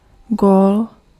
Ääntäminen
IPA : /ˈɡəʊl/